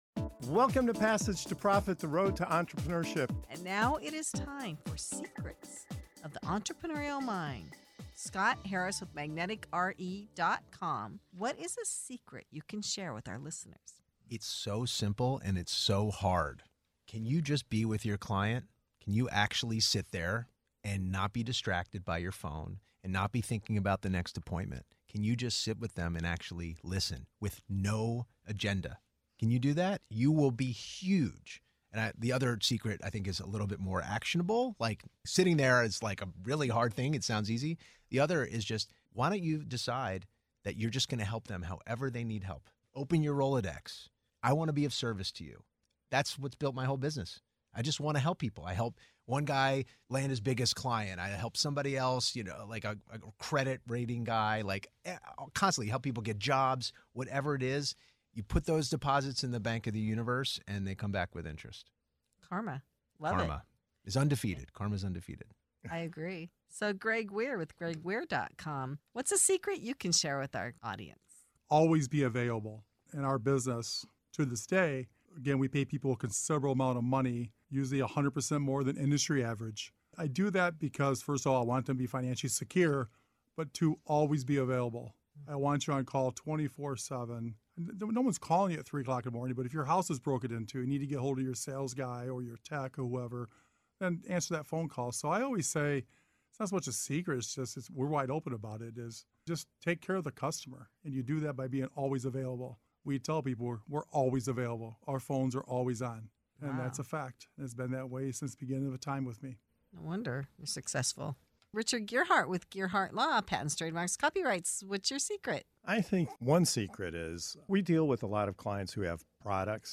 The Passage to Profit Show is a nationally syndicated weekly radio show and podcast about entrepreneurism and innovation from multiple perspectives.